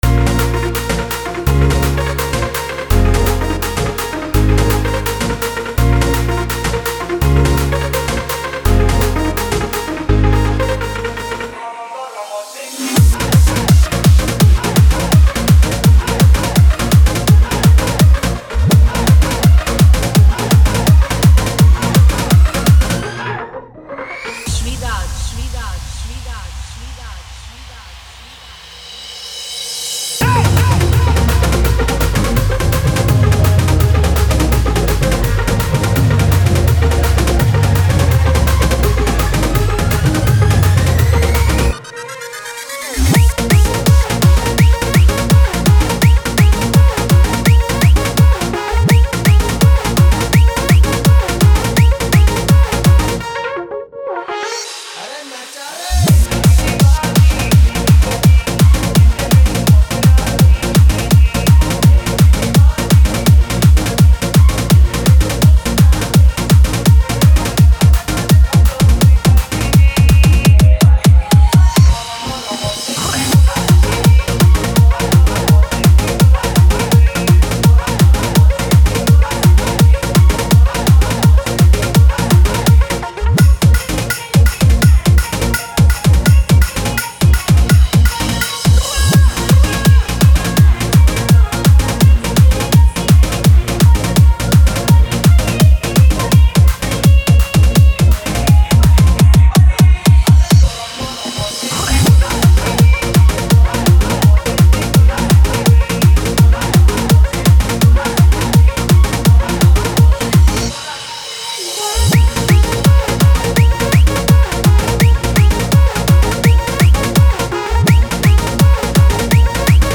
Ganesh Puja Special Dj 2023 Songs Download